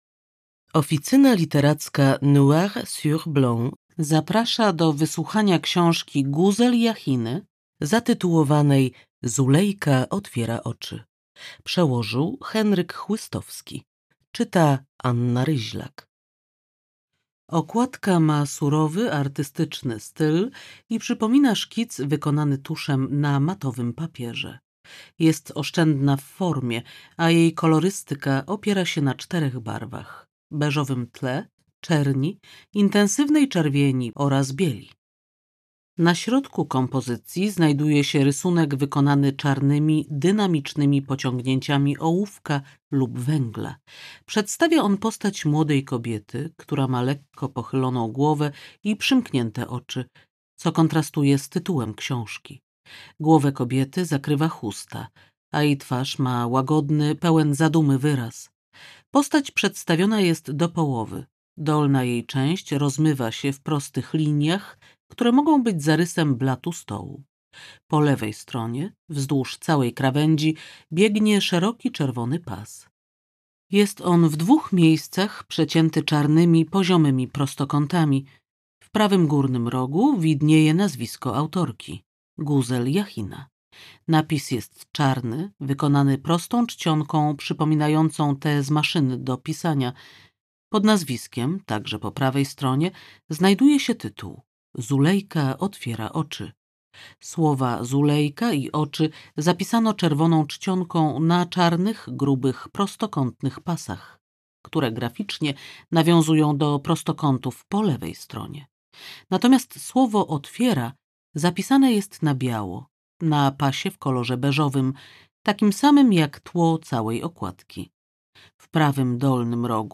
Zulejka otwiera oczy - Guzel Jachina - audiobook